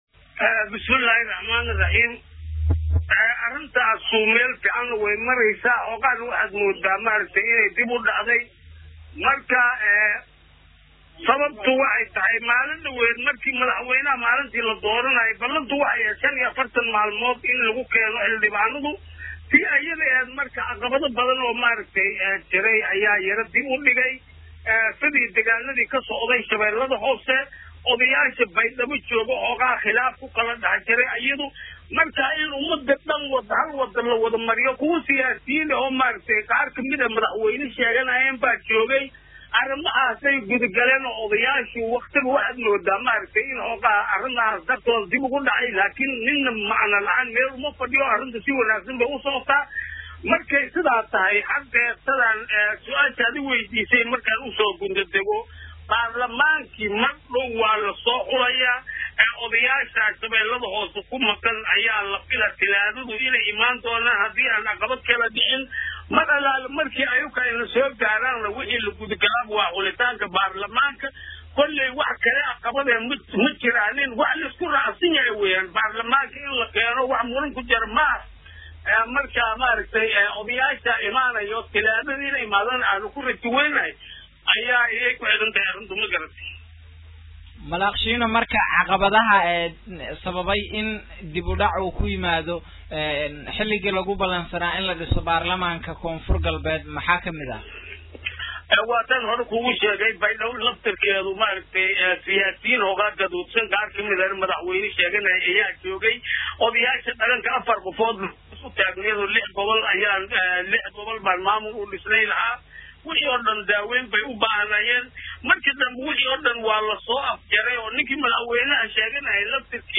Dhageyso Wareysi:Gollaha Guurtida Ee Dowlada KGS Oo Shaaciyey Xilliga Ay Bilaabayaan Xulista Xildhibaanada Baarlamanka KGS